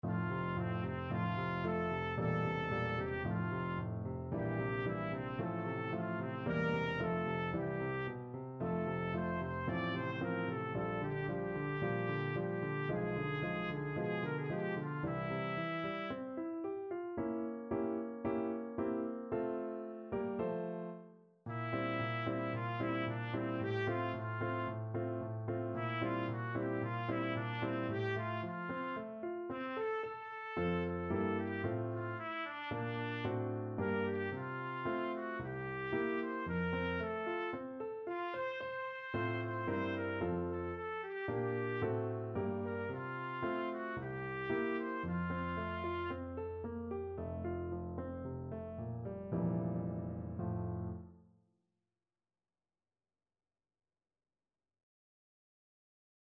Classical Beethoven, Ludwig van La Partenza, WoO 124 Trumpet version
Trumpet
2/4 (View more 2/4 Music)
~ = 56 Affettuoso
Classical (View more Classical Trumpet Music)